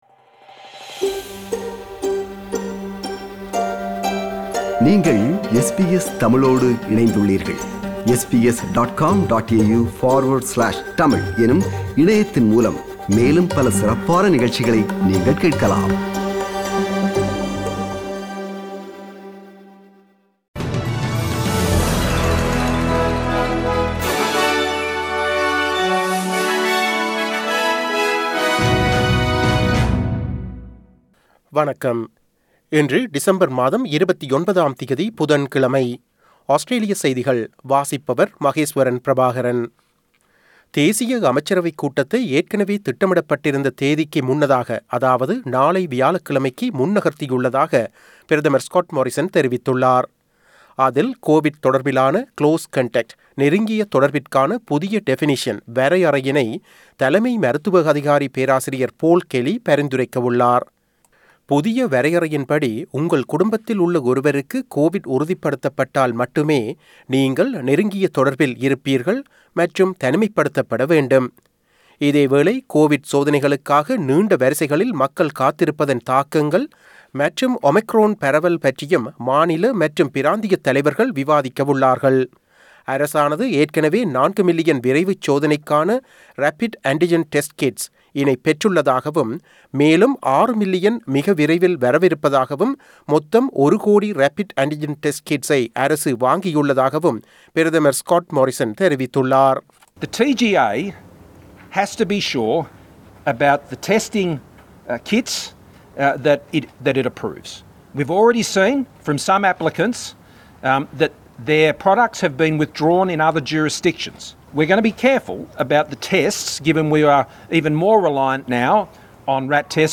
Australian news bulletin for Wednesday 29 December 2021.